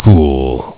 Worms speechbanks
amazing.wav